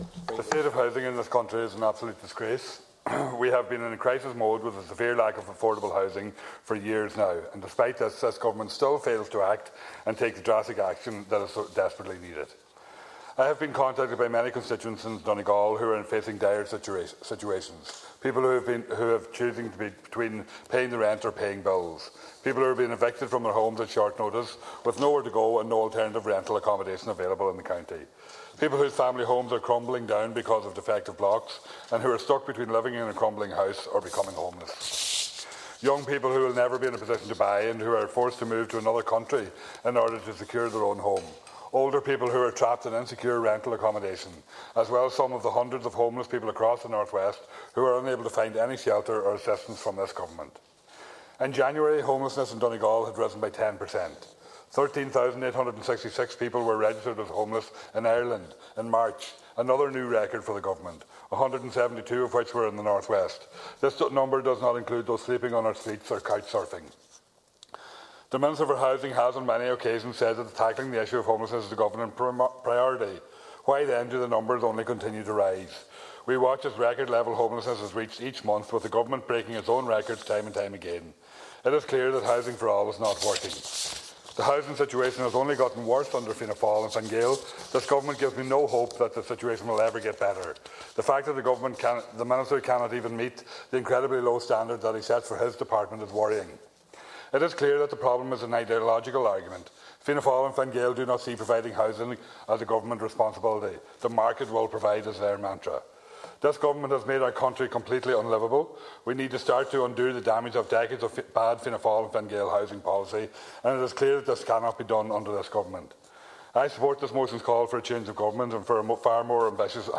He told the Dáil this week that there are many in Donegal facing difficult situations, some of which are worsened with the defective concrete block crisis.
Deputy Pringle questioned when government says housing is a priority why homeless figures continue to rise: